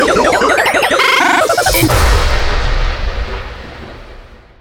Sound Buttons: Sound Buttons View : TELEPORTATION
drg_wrench_teleport.mp3